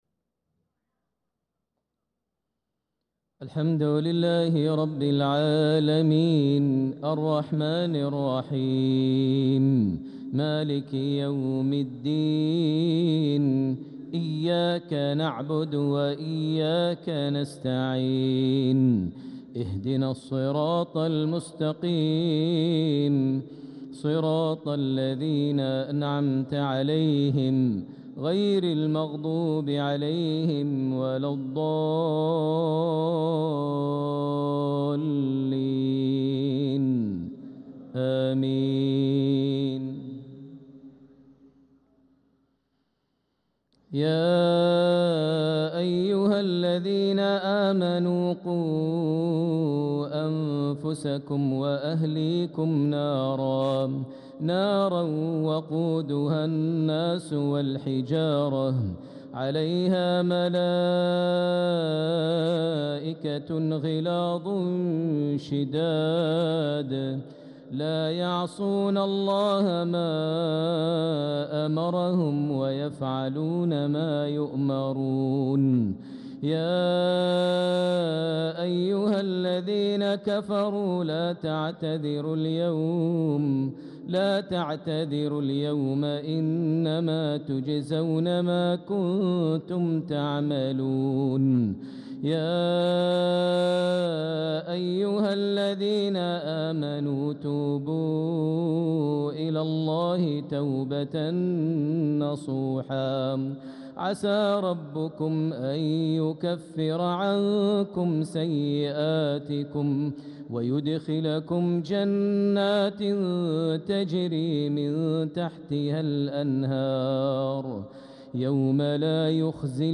صلاة العشاء للقارئ ماهر المعيقلي 24 ذو الحجة 1445 هـ
تِلَاوَات الْحَرَمَيْن .